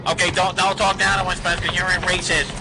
Nerd Impression 2